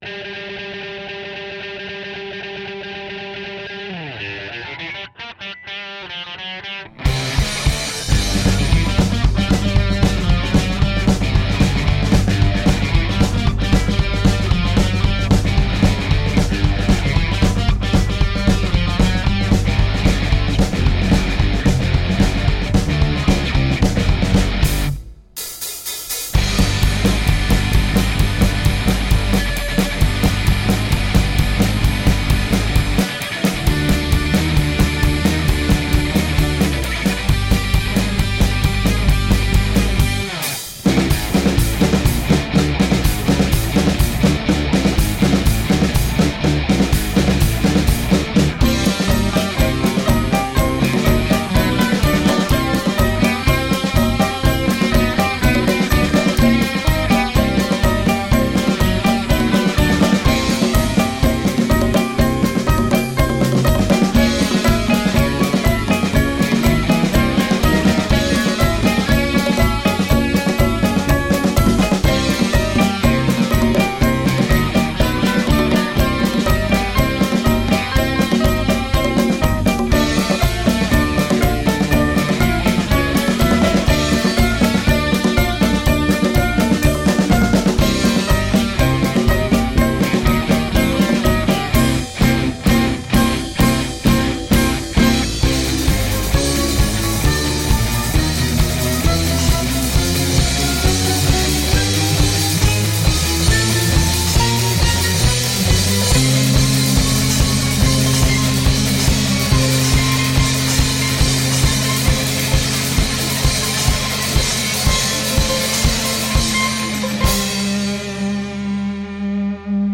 A whole new kind of instrumental rock.
Tagged as: Alt Rock, Hard Rock, Grungy Rock, Instrumental